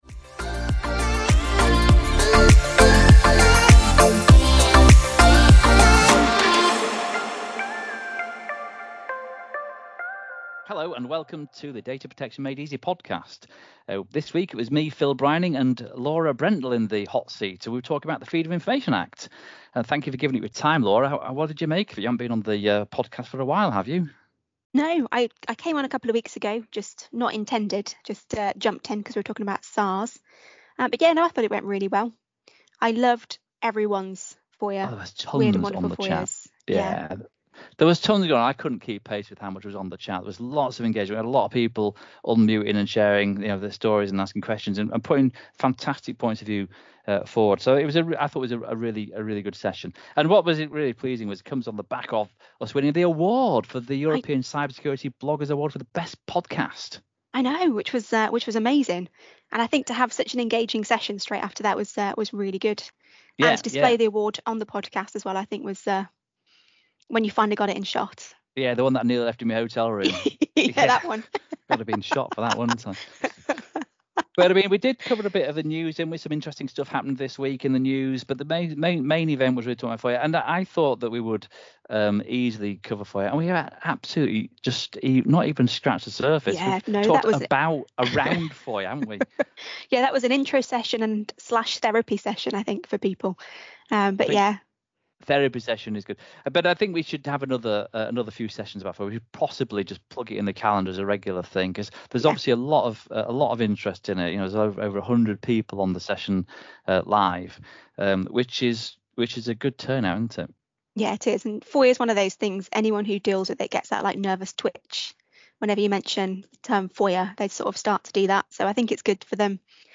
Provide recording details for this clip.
On this week's episode the crowd was fired up and shared stories of their own weird and wonderful requests.